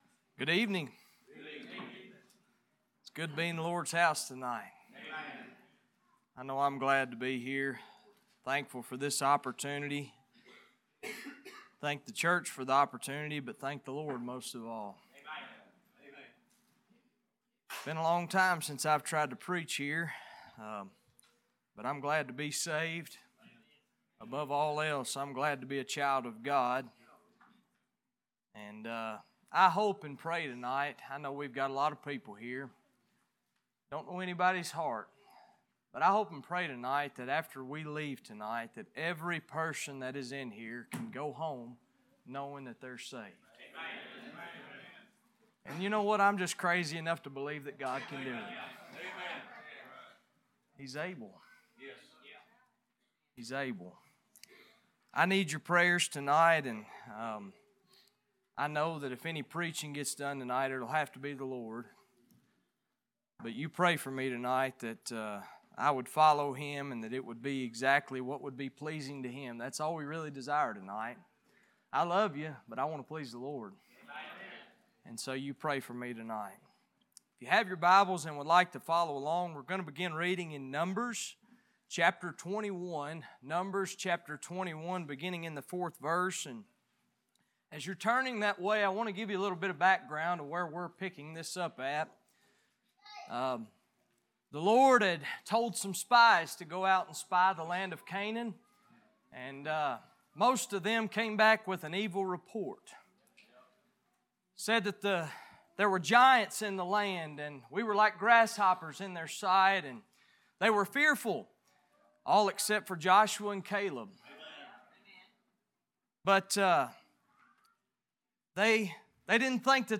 Series: Revival Meeting
James 1:13-15 Service Type: Worship « We Have Choices What is Next?